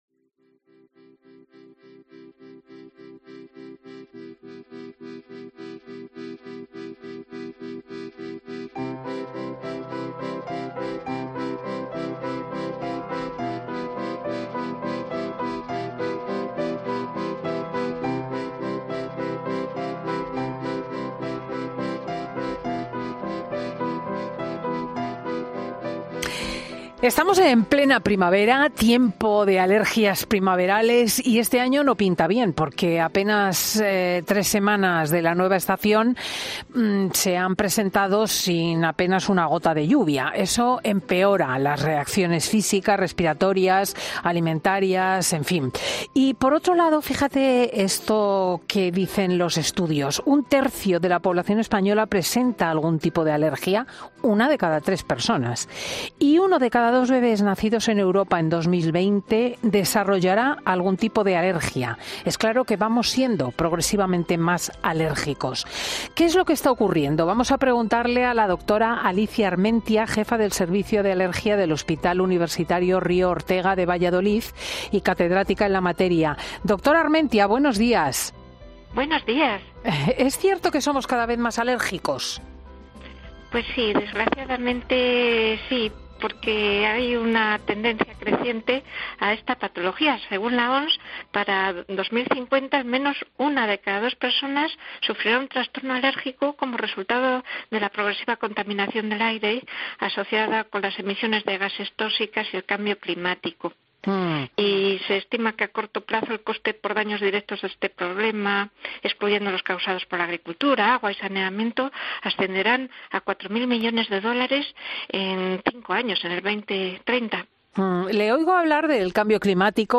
En 'Fin de Semana' hablamos con una alergóloga que nos da las claves de por qué estamos notando más síntomas en nuestras alergías a lo largo de los años